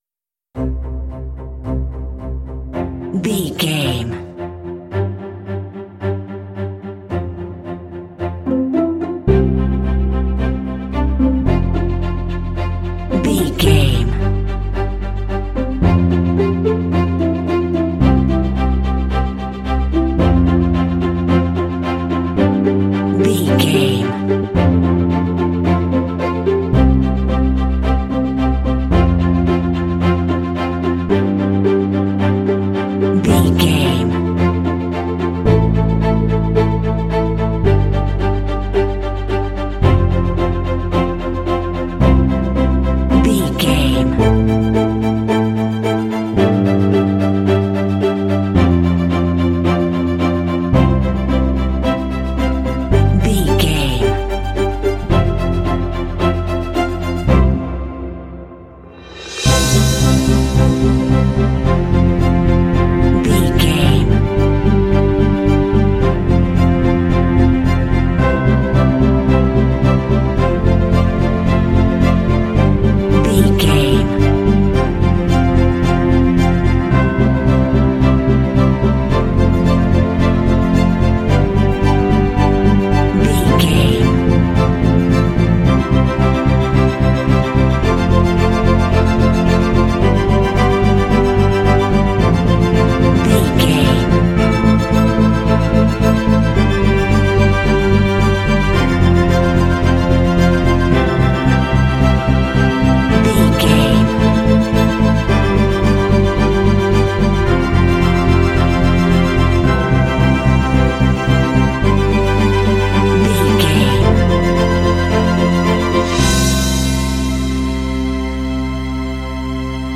Ionian/Major
optimistic
happy
bright
contemporary underscore